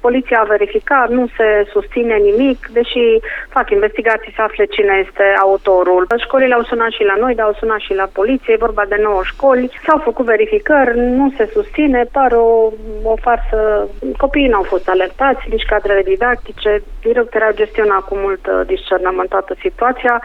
Marinela Marc, Inspector Școlar General: